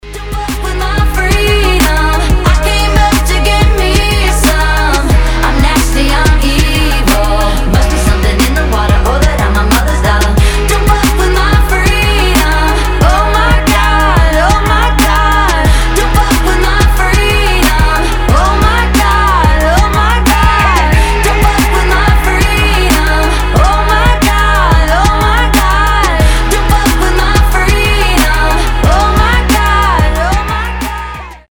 • Качество: 320, Stereo
поп
громкие
женский вокал
Trap